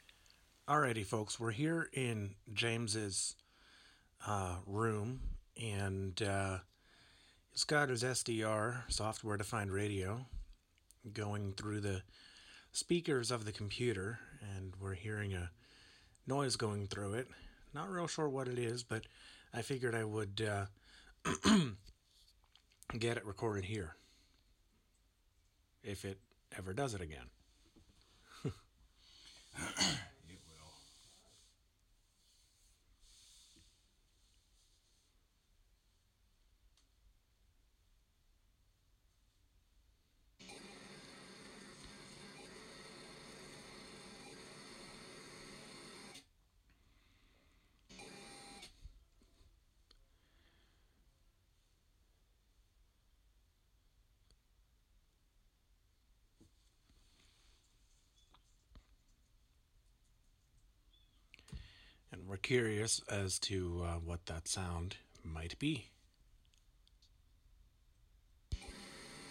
strange sound